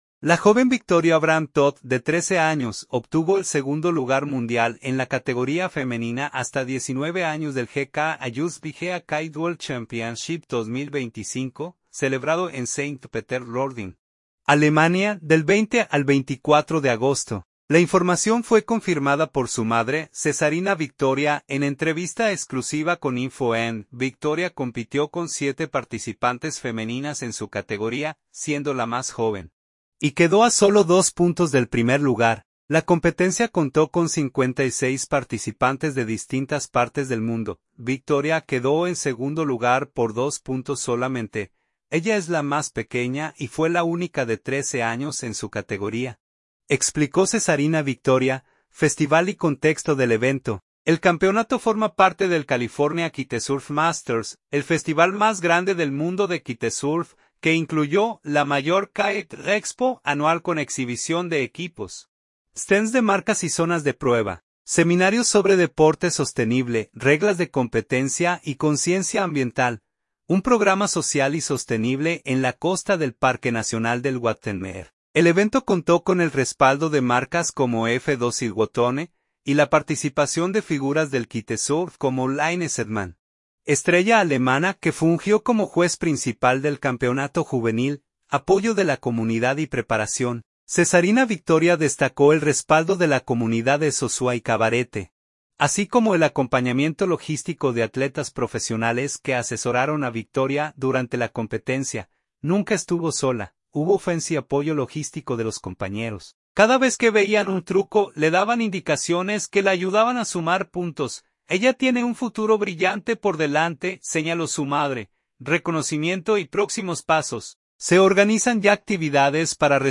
en entrevista exclusiva con InfoENN.